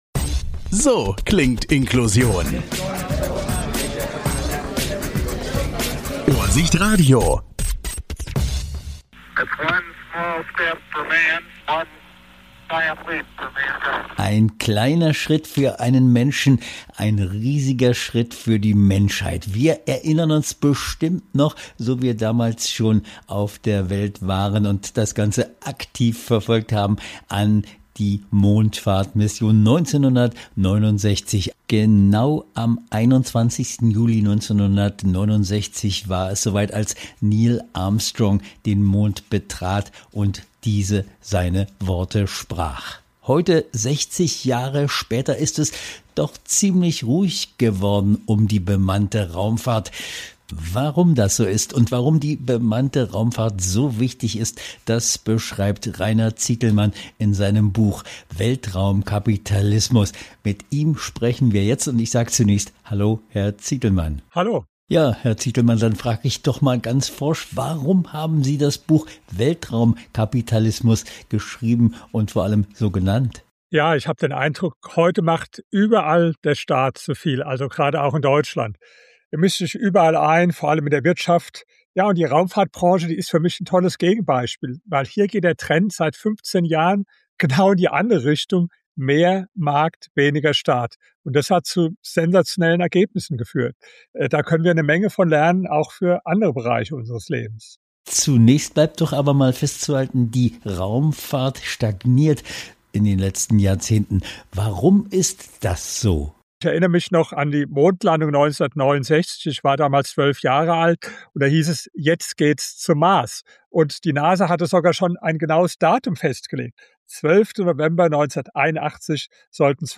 Episode #258 - Interview im ohrsicht podcast